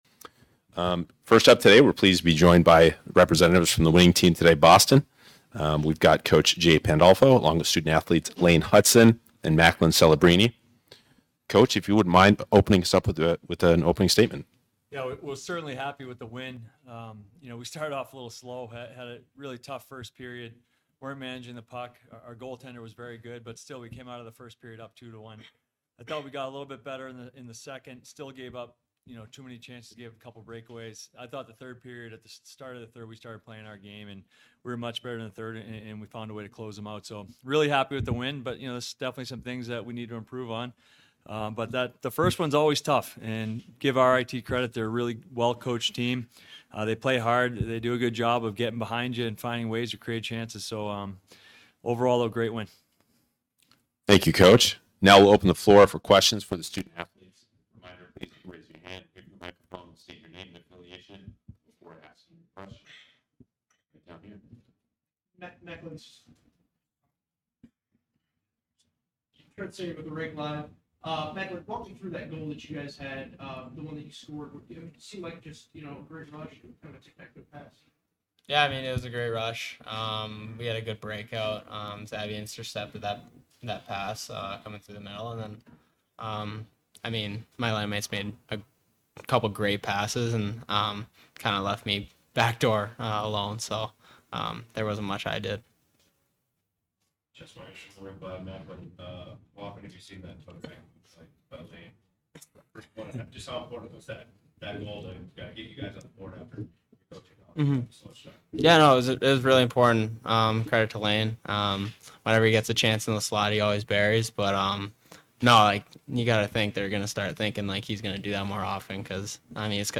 Men's Ice Hockey // NCAA Regional vs. RIT Press Conference - Boston University Athletics